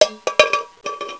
Bottle.wav